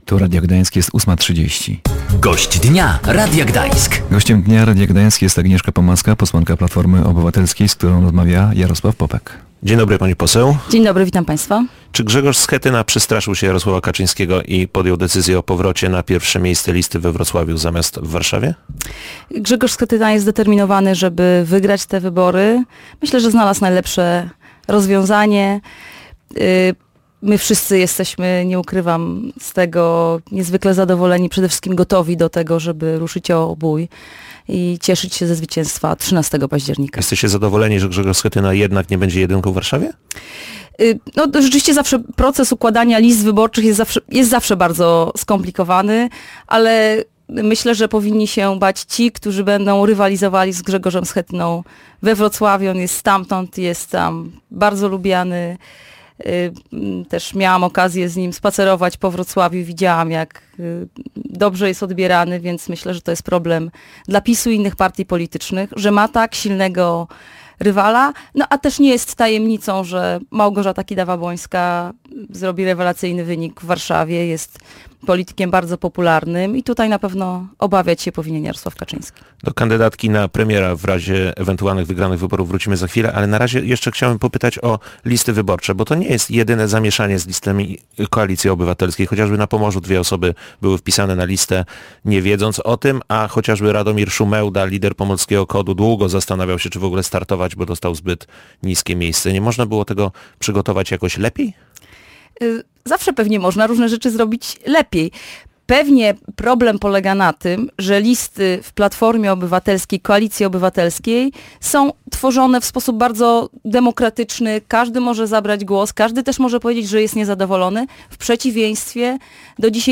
Między innymi na to pytanie odpowiedziała posłanka Agnieszka Pomaska, kandydatka Koalicji Obywatelskiej do Sejmu z Gdańska.
Agnieszka Pomaska zapewniała na antenie Radia Gdańsk, że Koalicja Obywatelska w przypadku wygrania wyborów zamierza utrzymać programy socjalne.